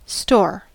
Ääntäminen
IPA : /ˈstɔɹ/